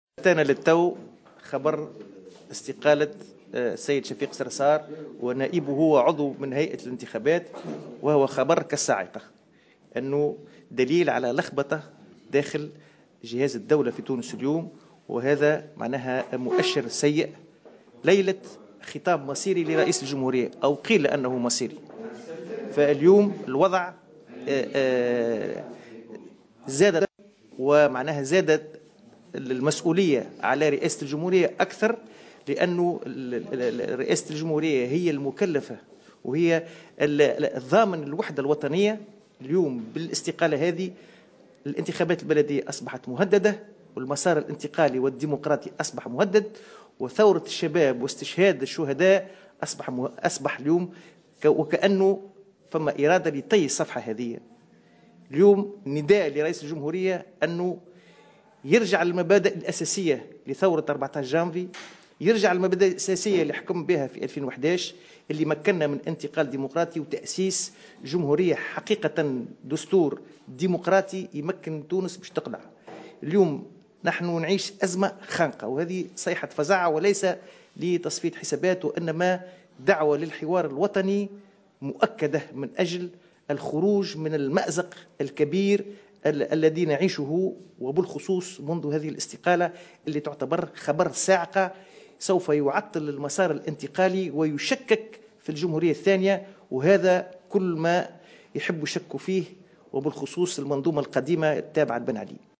وأضاف اليوم في تصريح لمراسل "الجوهرة أف أم" على هامش ندوة صحفية عقدها الحزب أن هذه الاستقالة مؤشر سيء ودليل على وجود "لخبطة" داخل جهاز الدولة، داعيا رئاسة الجمهورية إلى تحمّل مسؤولياتها وإلى فتح حوار وطني للخروج من الأزمة التي تمرّ بها البلاد.